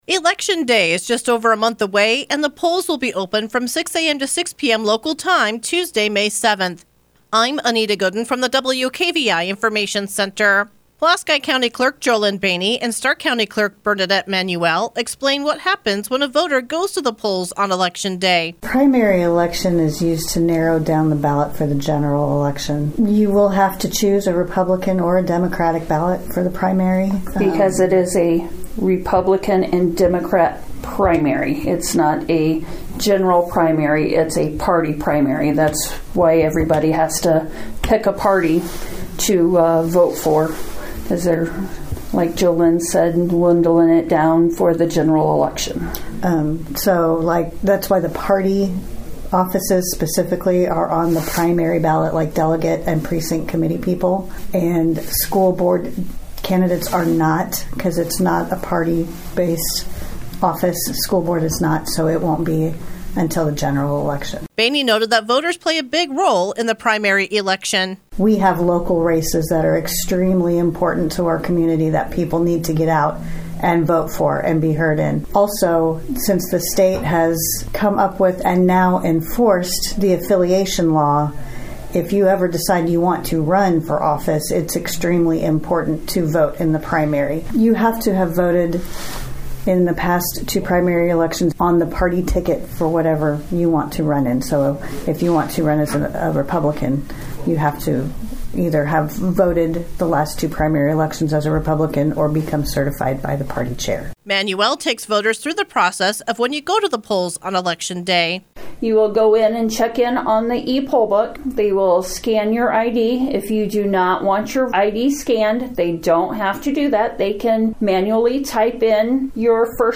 Best Election Coverage (November 2023 and May 2024)WKVI-FM (Knox) – Election Information for Voters